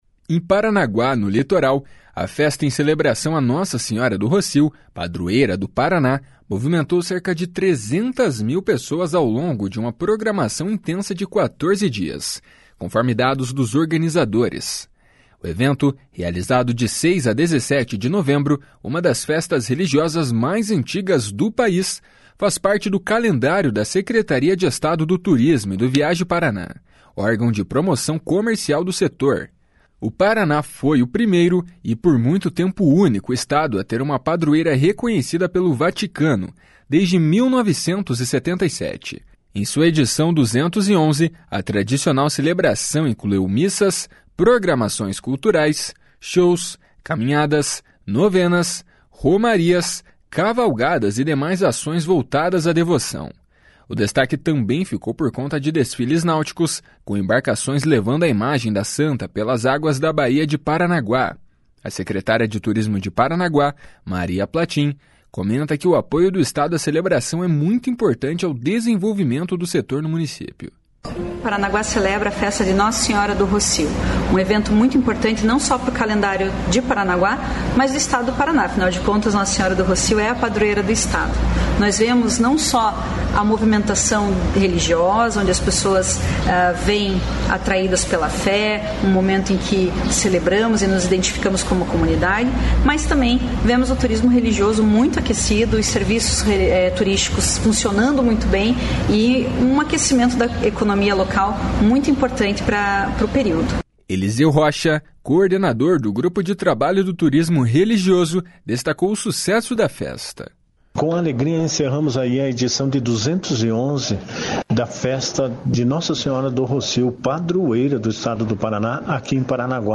A secretária de Turismo de Paranaguá, Maria Plahtyn, comenta que o apoio do Estado à celebração é muito importante ao desenvolvimento do setor no município.